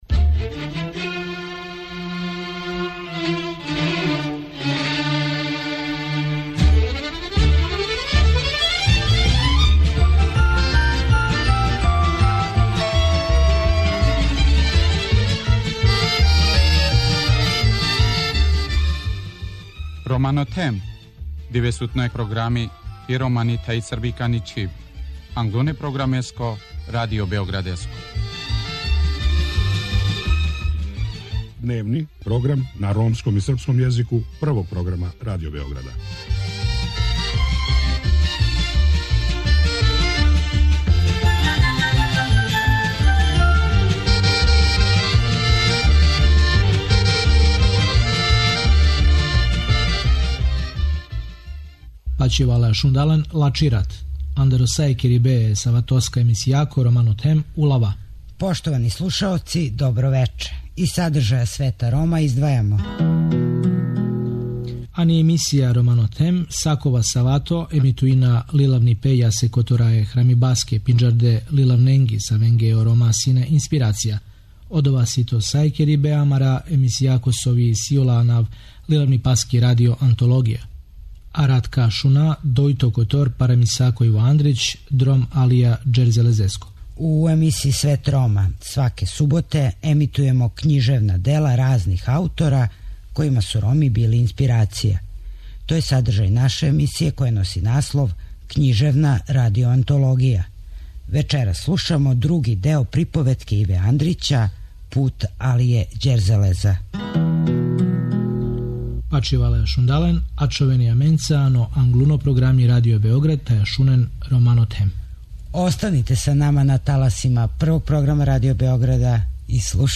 Вечерас слушамо други део приповетке Иве Андрића – Пут Алије Ђерзелеза.